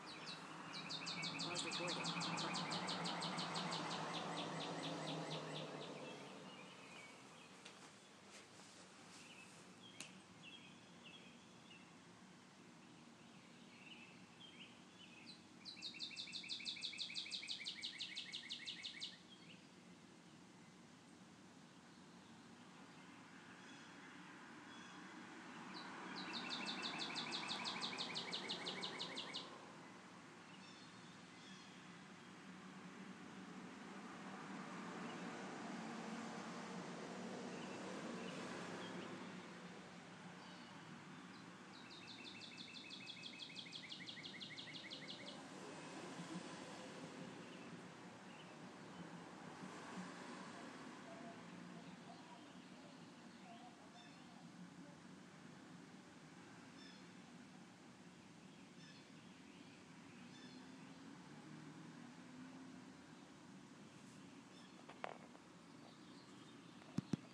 Cardinal and birds March 31, 2012